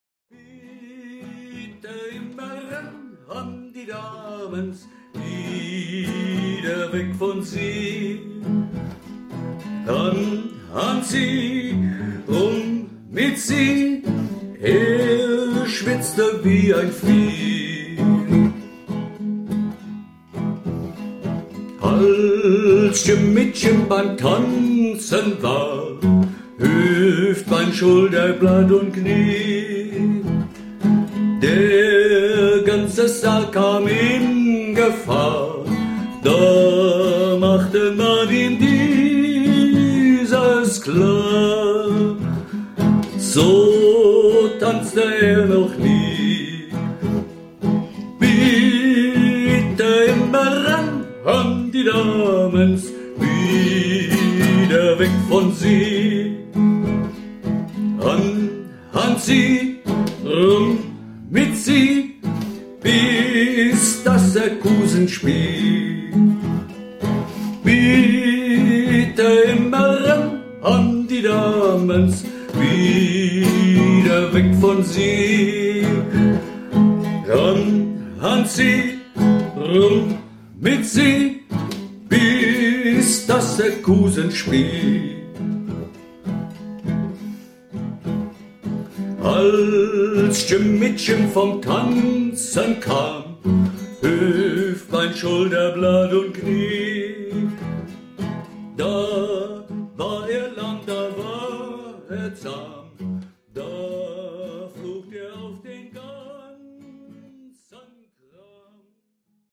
Werkstatt - Aufnahmen 21